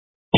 Invité: